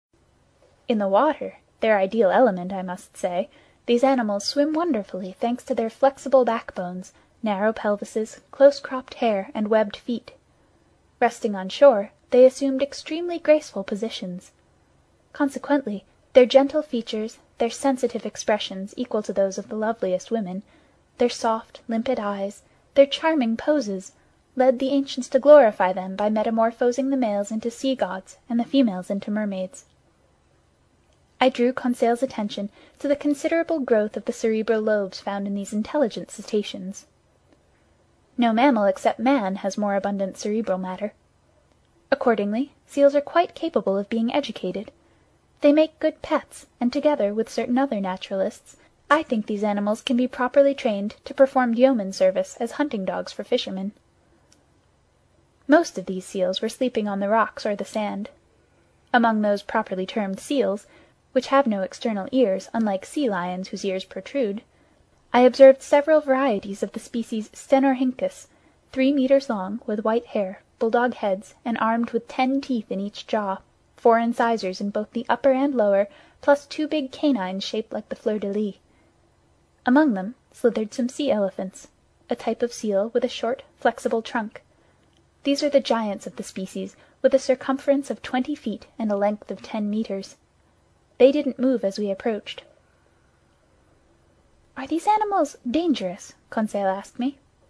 英语听书《海底两万里》第450期 第27章 南极(9) 听力文件下载—在线英语听力室
在线英语听力室英语听书《海底两万里》第450期 第27章 南极(9)的听力文件下载,《海底两万里》中英双语有声读物附MP3下载